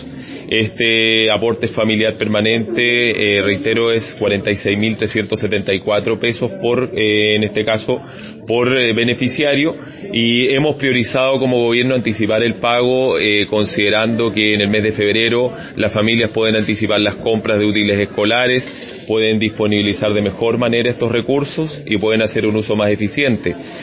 En este sentido, el Intendente de Los Ríos, César Asenjo, quien llegó hasta la Caja de Compensación Los Héroes, dijo que se ha tomado la decisión de adelantar el pago, debido a los gastos que tienen las personas ad portas del ingreso a clases.
intendente-1.mp3